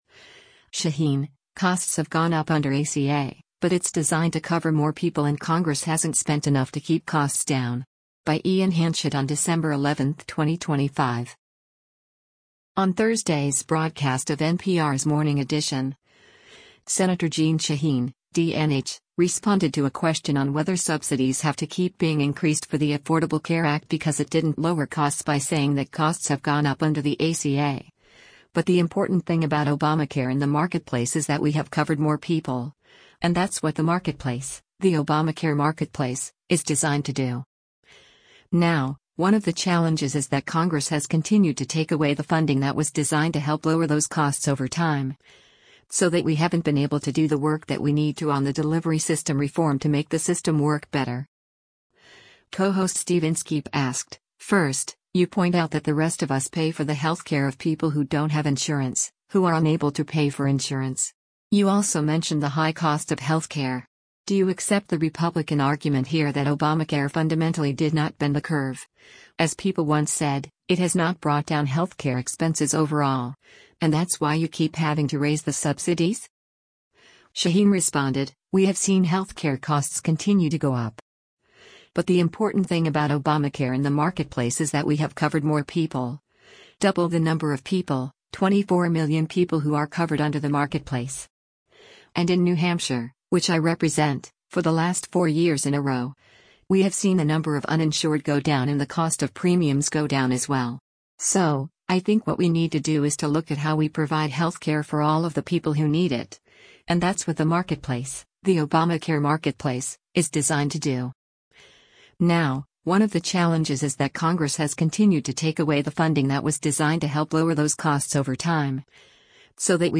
On Thursday’s broadcast of NPR’s “Morning Edition,” Sen. Jeanne Shaheen (D-NH) responded to a question on whether subsidies have to keep being increased for the Affordable Care Act because it didn’t lower costs by saying that costs have gone up under the ACA, “But the important thing about Obamacare and the marketplace is that we have covered more people,” “and that’s what the marketplace, the Obamacare marketplace, is designed to do.